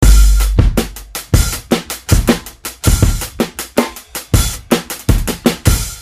Tag: 80 bpm Hip Hop Loops Drum Loops 1.01 MB wav Key : Unknown